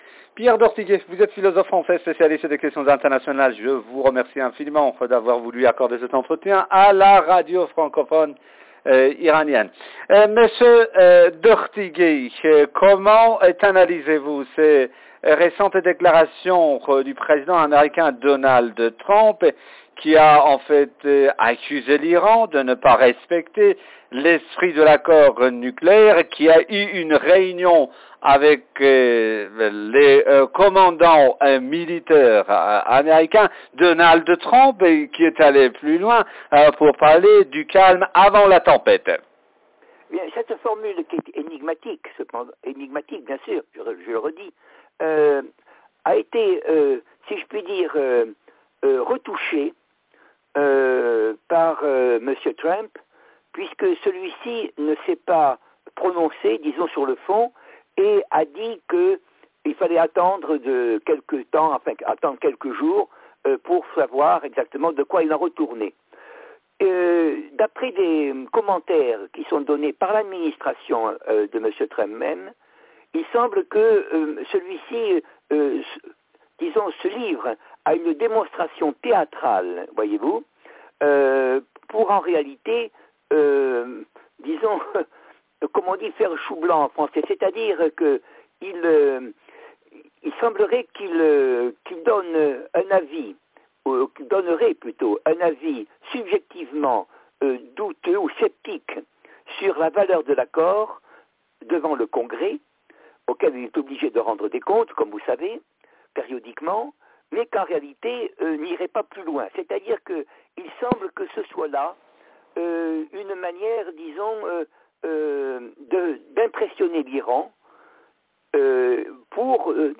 analyste politique français